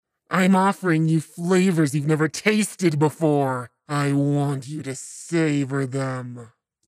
Right now in the recording I'm trying to do my best monster voice, but I'm not super happy with it.